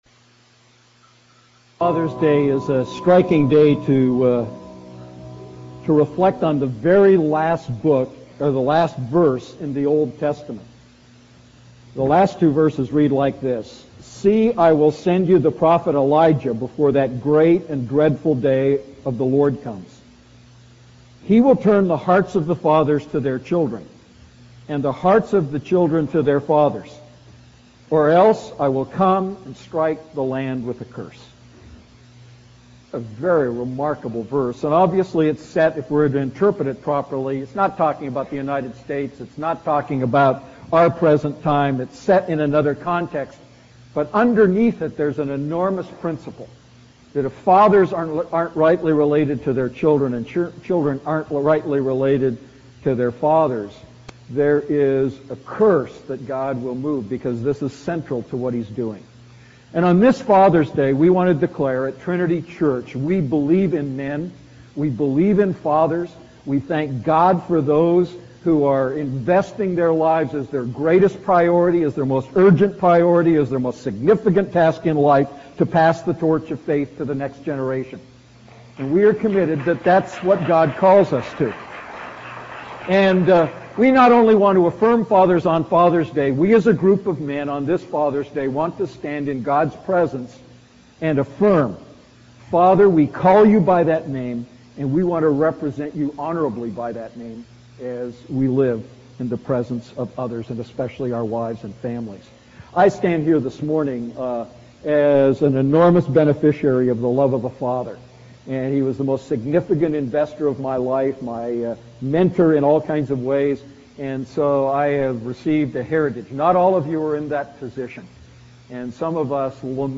A message from the series "Defining Moments."